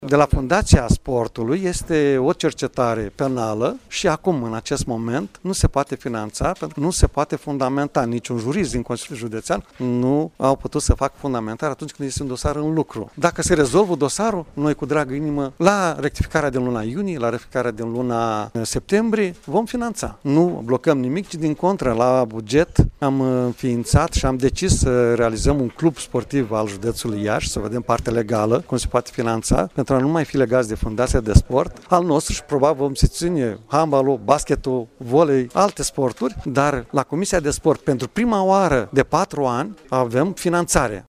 Afirmația a fost făcută, astăzi, în plenul Consiliului Județean Iași, de președintele instituției, Maricel Popa.